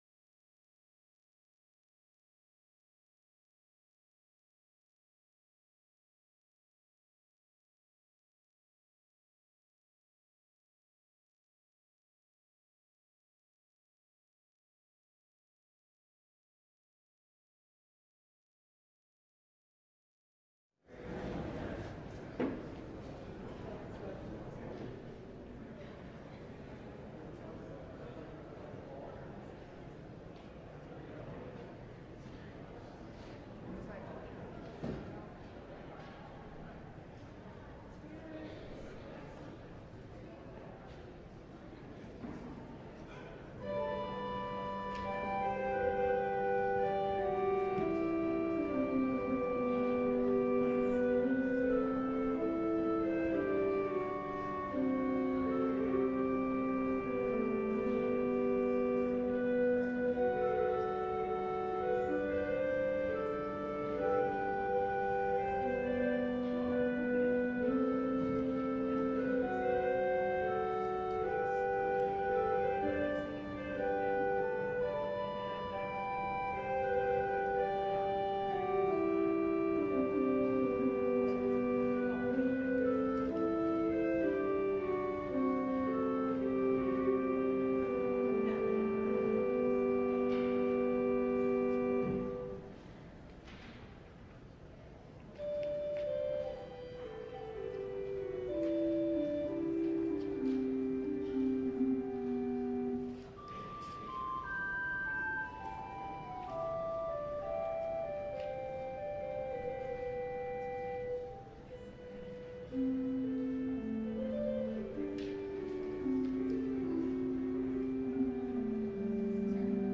LIVE Morning Worship Service - The Way of Peace